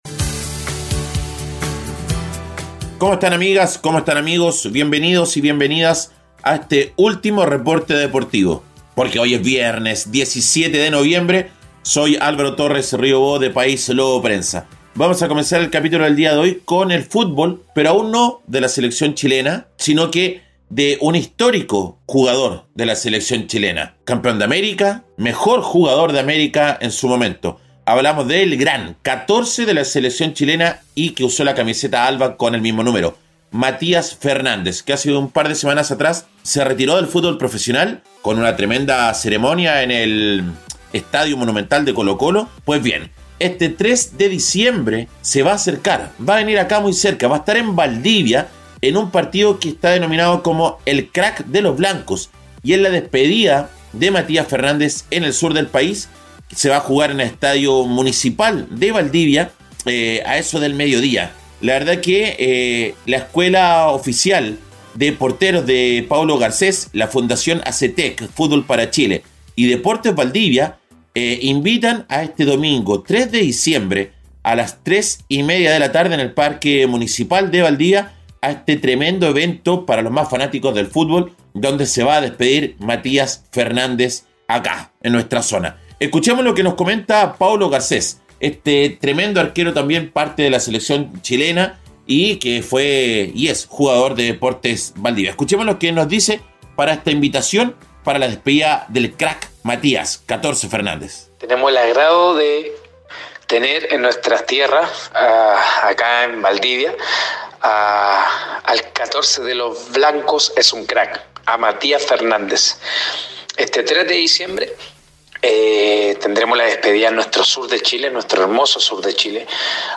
En este episodio, les traemos un breve reporte con las "deportivas" más destacadas de las últimas 24 horas.